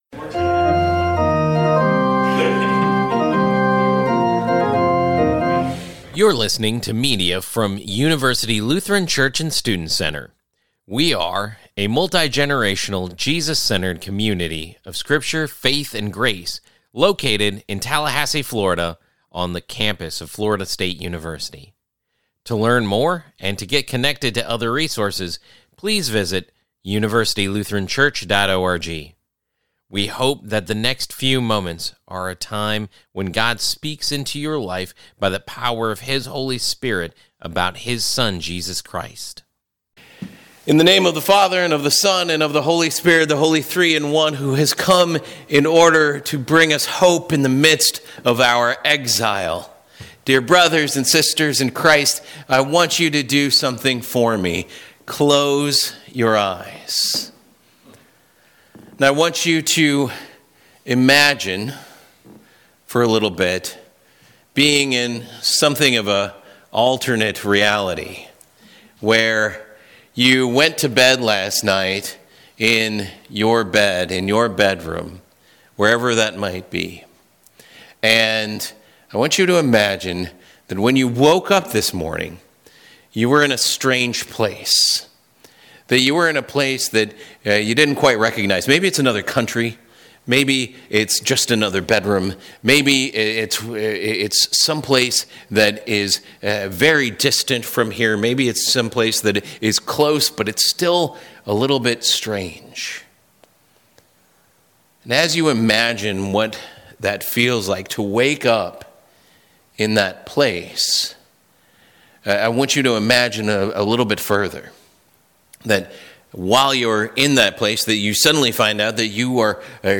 We start our “Advent in Babylon” sermon series, considering how our practice of Advent is paralleled by the people Israel in exile in Babylon.